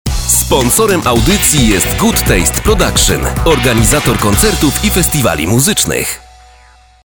Mężczyzna 30-50 lat
Mocny i dynamiczny tembr głosu z pewnością nada charakter Twojemu nagraniu.